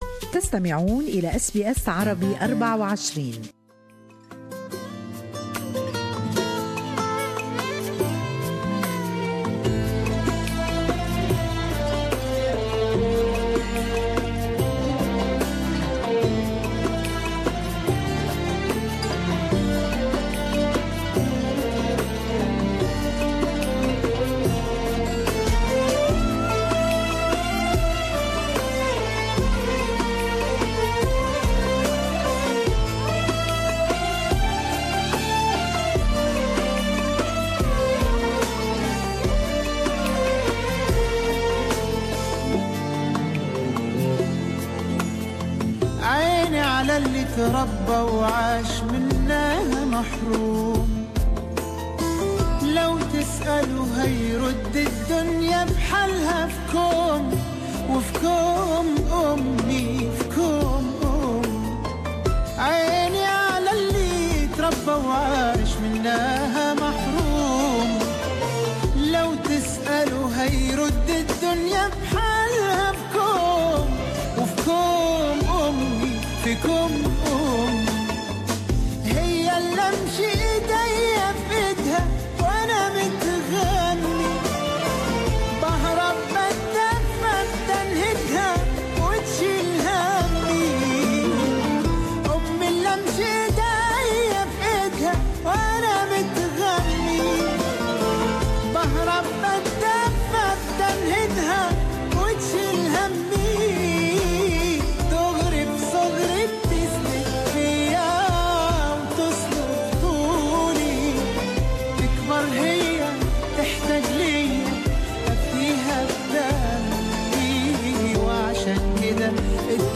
تحتفل أستراليا اليوم بعيد الأمهات، تعرفوا في التقرير التالي الى كيف نشأت فكرة الإحتفال بهذا العيد المميز، وفي التقرير مختارات لأجمل أغاني عيد الأم.